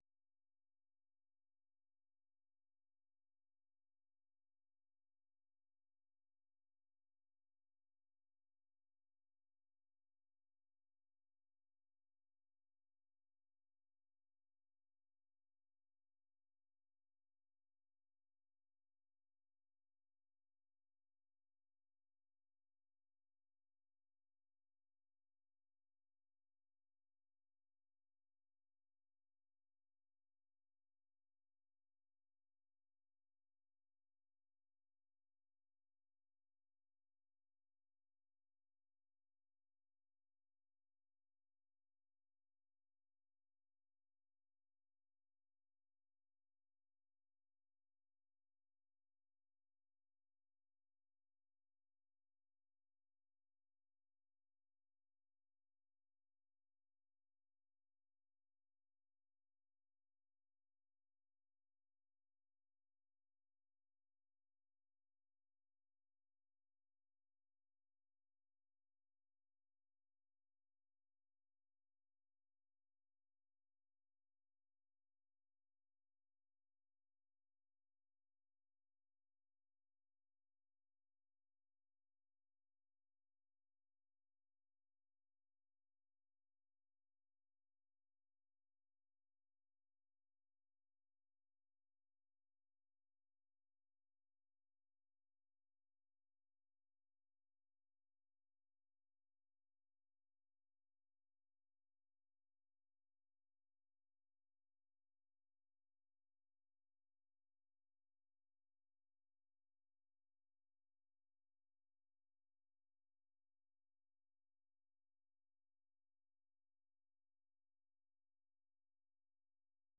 Allocution du président Donald Trump devant la session conjointe du Congrès